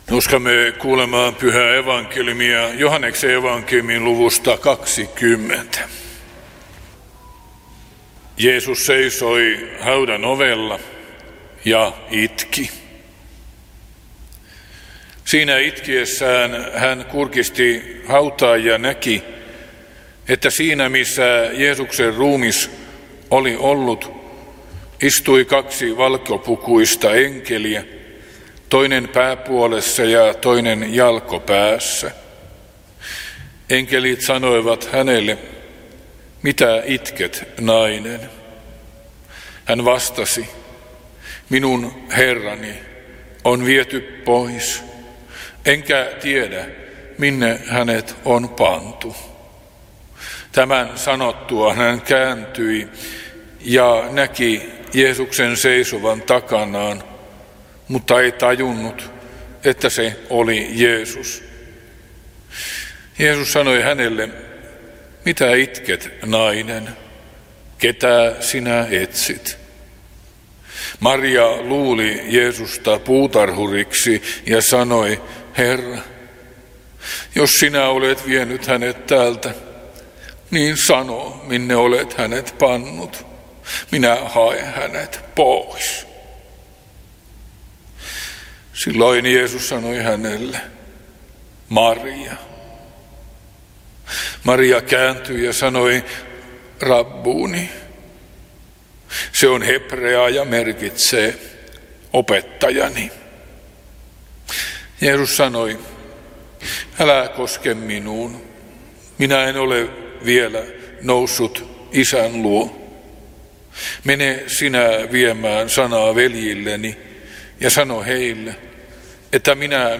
saarna Teuvassa II pääsiäispäivänä Tekstinä Joh. 20:11–18